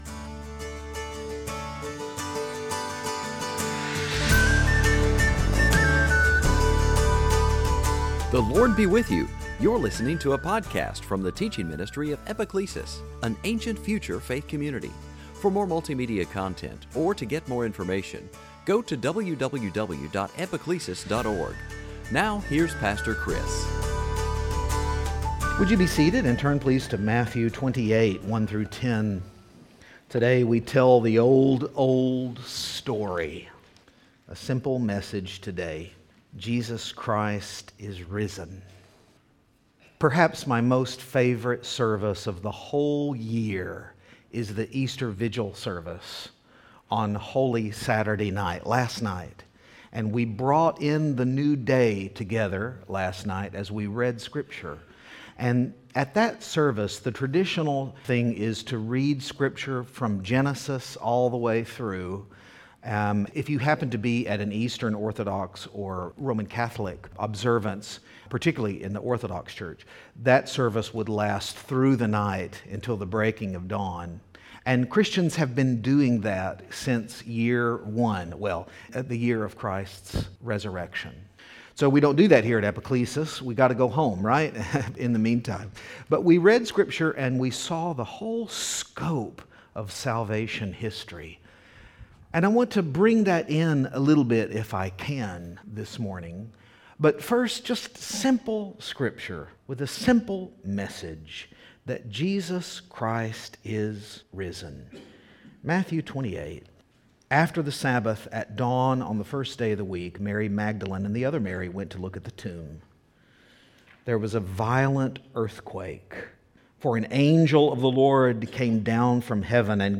Series: Sunday Teaching On this Easter Sunday